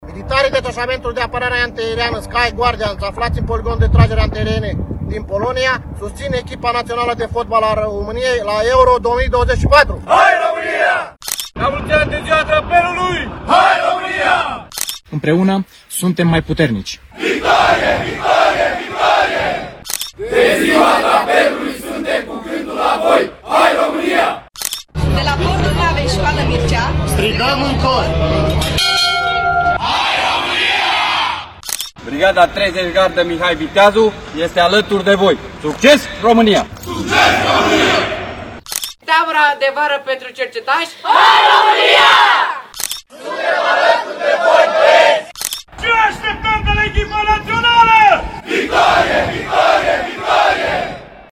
Tricolorii primesc mesaje de încurajare de la militarii români.  „Hai, România” şi „Succes”, le-au transmis soldații noștri aflaţi în misiuni în Polonia, Republica Centrafricană, la bordul Navei Şcoală Mircea sau chiar pe Vârful Negoiu.